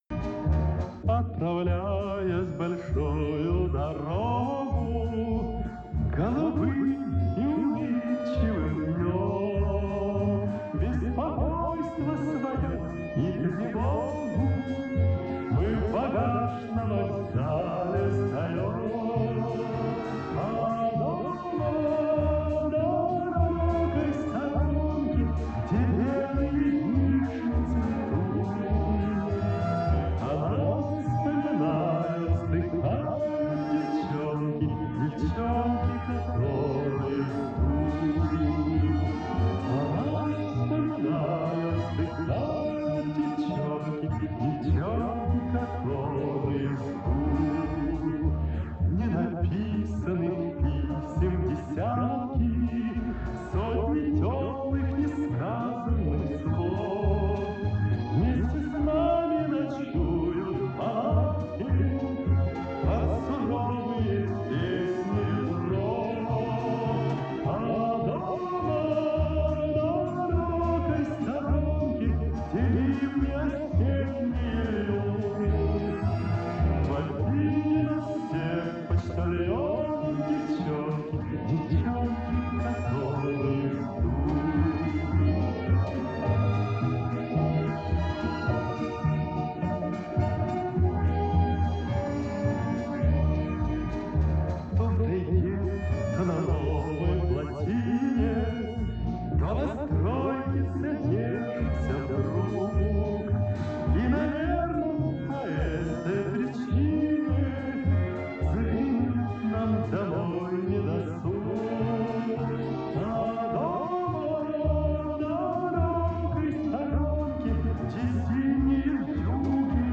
А эхо, да, Вы правы, не было.